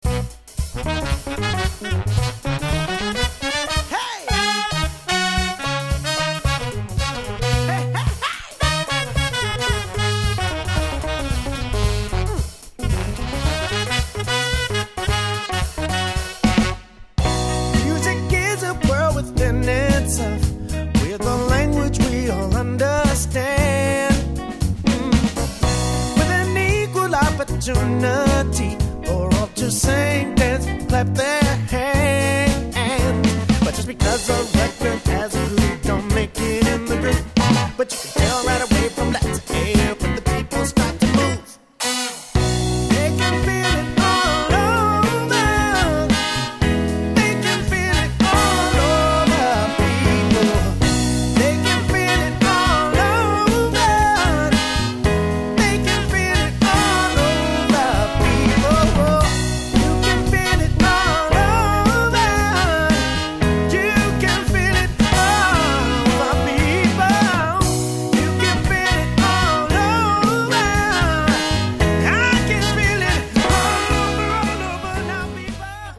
MOTOWN & R&B